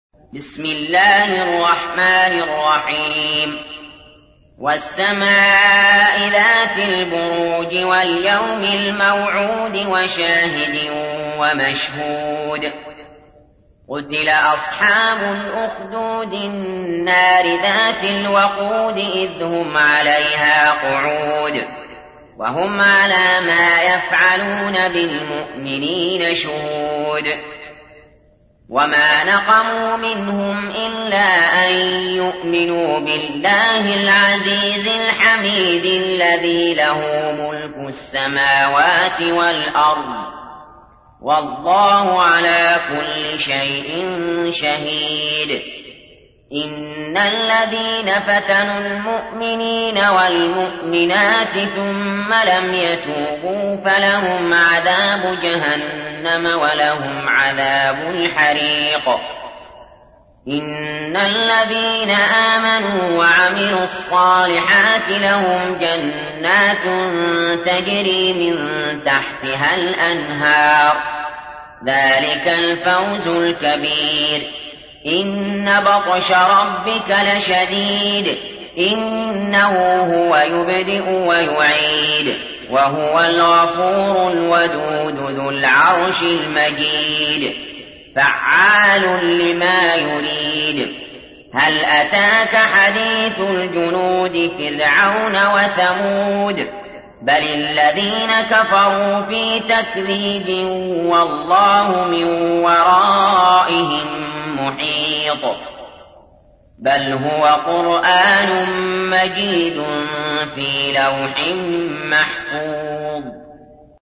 تحميل : 85. سورة البروج / القارئ علي جابر / القرآن الكريم / موقع يا حسين